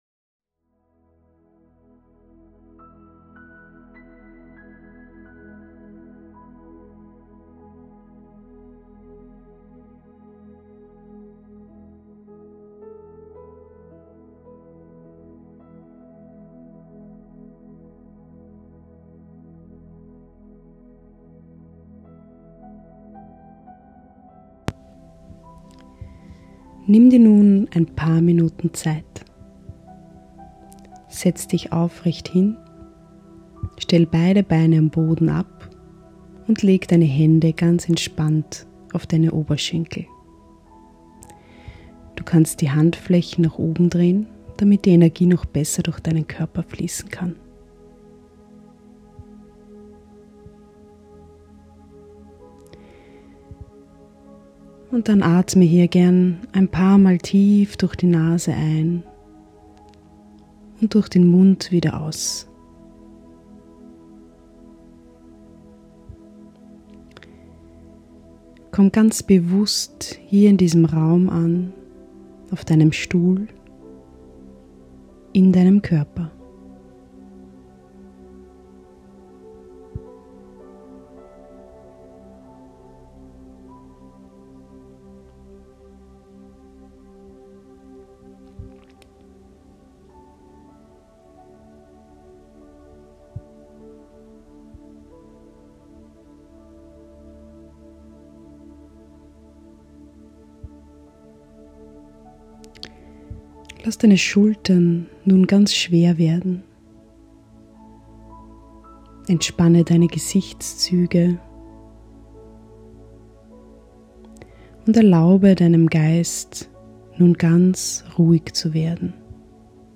Meditation | CULUMNATURA